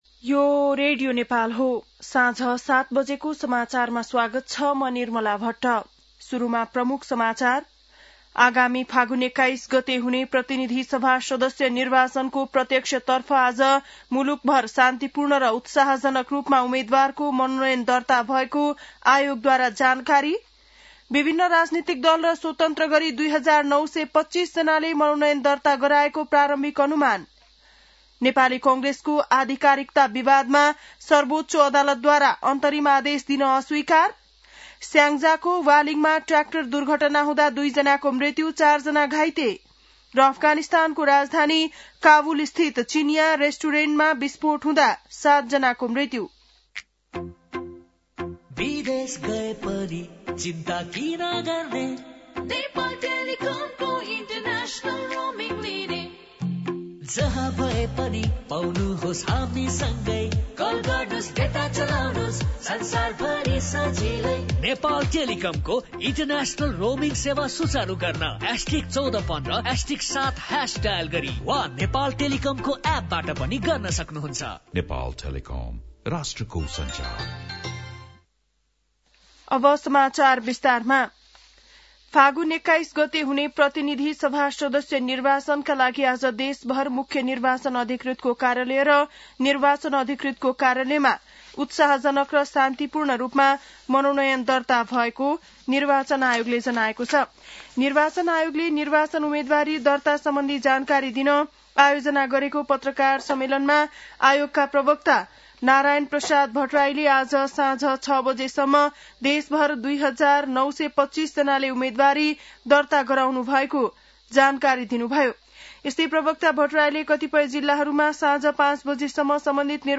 बेलुकी ७ बजेको नेपाली समाचार : ६ माघ , २०८२